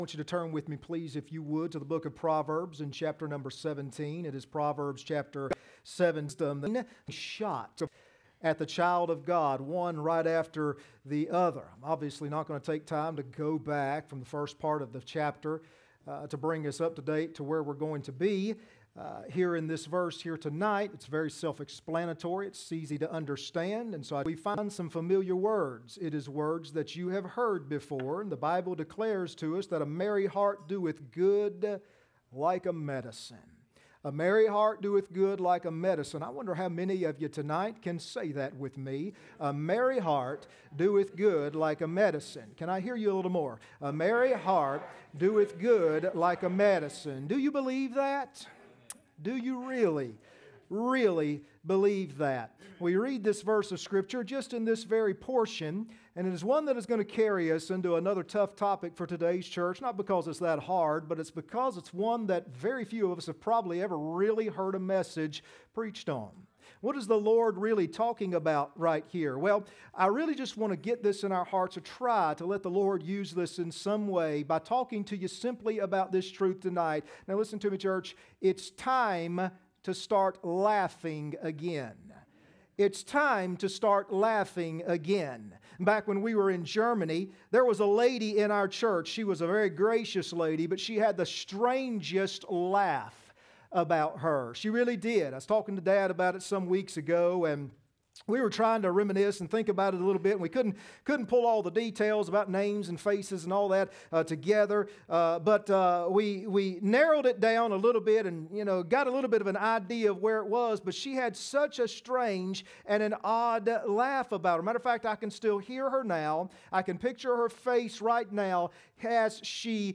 Passage: Proverbs 17:22 Service Type: Sunday Evening